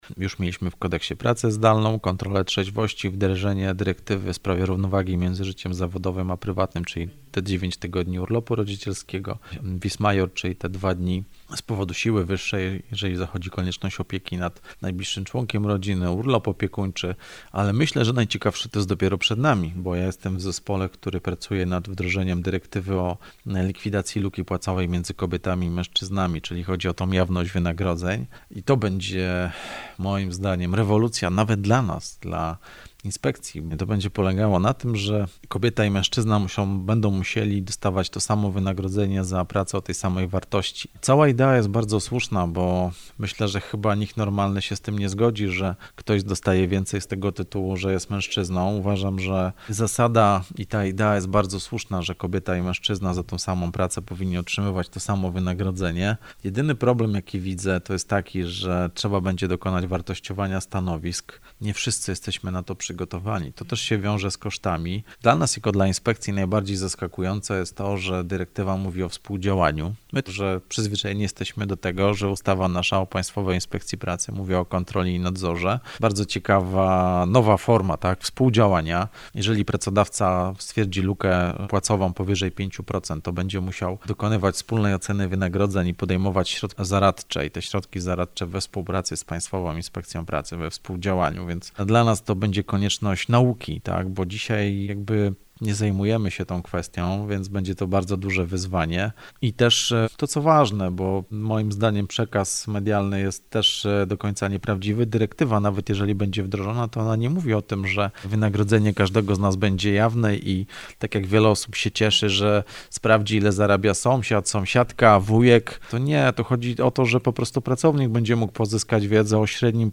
-Te zmiany są istotne, jednak największe rewolucje wprowadzono 2 lata temu, mówi minister Marcin Stanecki – Główny Inspektor Pracy.